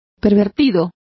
Complete with pronunciation of the translation of pervert.